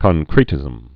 (kŏn-krētĭzəm, kŏng-)